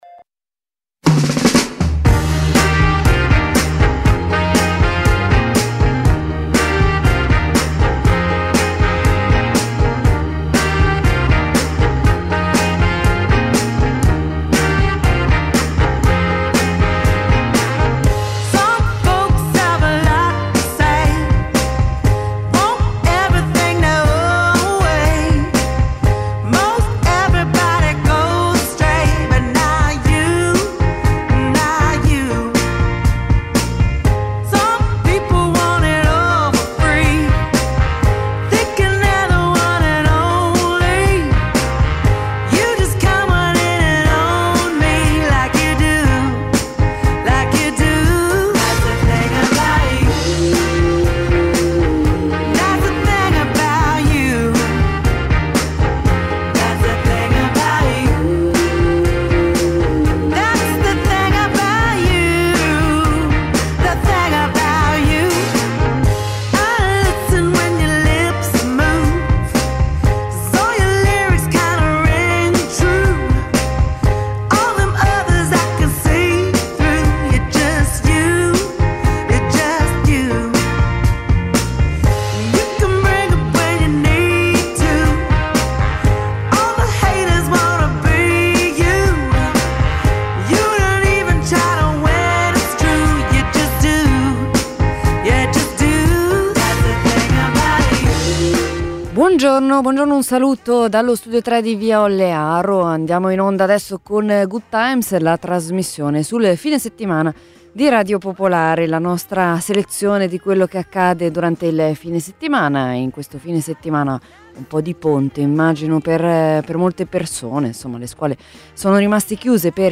Intervento a RADIO POPOLARE nella trasmissione Good Times (al minuto 14:17)